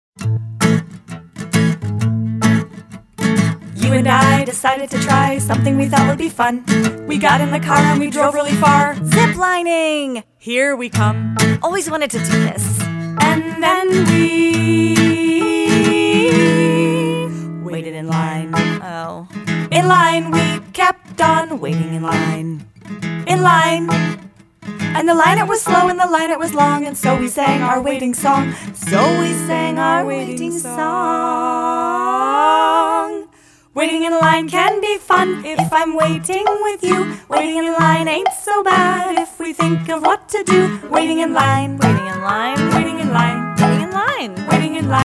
All tracks except Radio Edits include scripted dialogue.